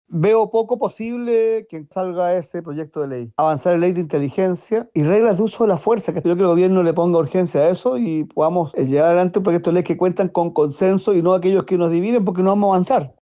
Desde el oficialismo acusaron “medidas dilatorias” en las últimas sesiones. Así lo manifestó la diputada y jefa de bancada del Frente Amplio (FA), Lorena Fries.